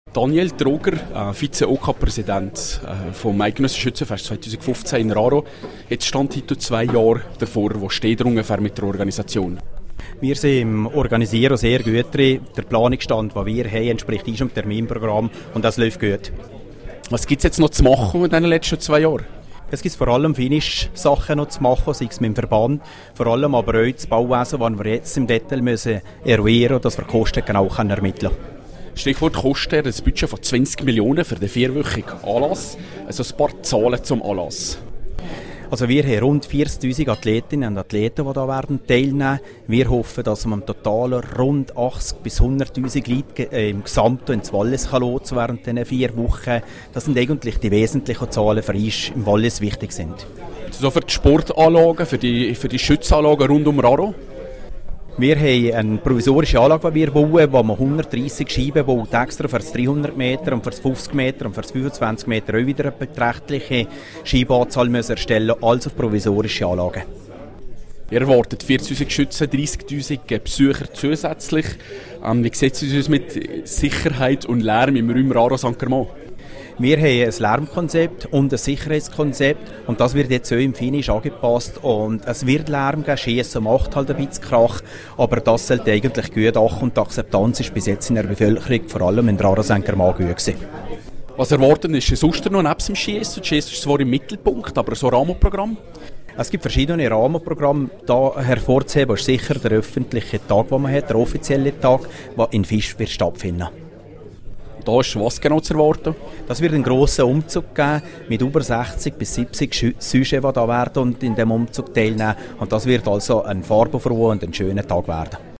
Interview zum Thema (Quelle: rro) Medienmitteilung (Quelle: Eidgenössisches Schützenfest Raron/Visp)